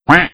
rocket_blackbox_explode3.wav